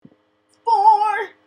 funny voices